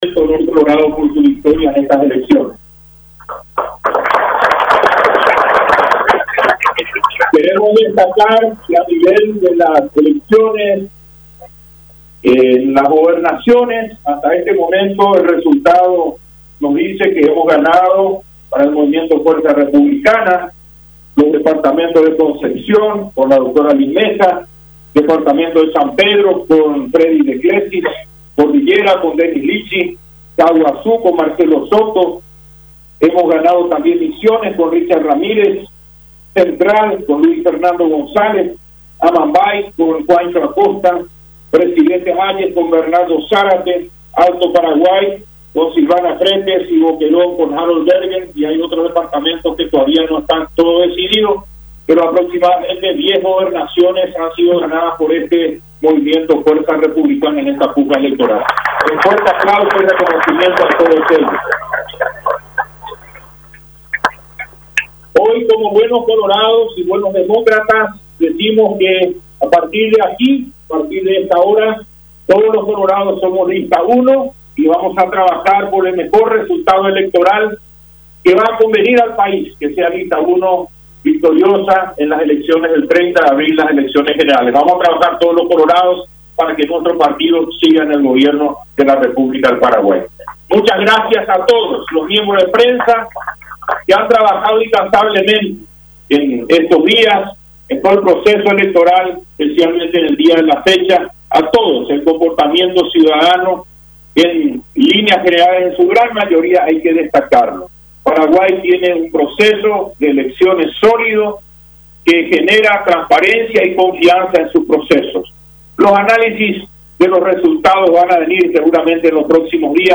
“Hoy como buenos colorados y a partir de aquí todos somos lista 1. Vamos a trabajar juntos para que el Partido Colorado siga siendo gobierno”, aseveró Wiens en su discurso en el PC de Fuerza Republicana, a poco de confirmarse su derrota mediante el sistema TREP, afirmando que reconoce el resultado electoral y felicitando al movimiento Honor Colorado.
54-DISCURSO-WIENS.mp3